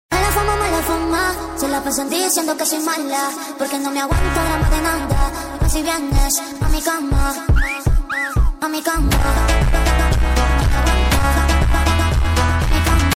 Toyota Engine ????